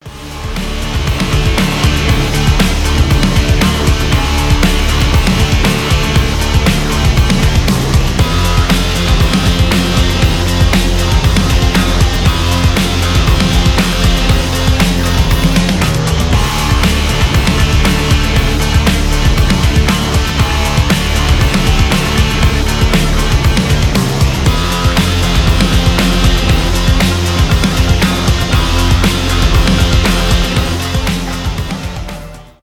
фолк-рок
без слов
инструментальные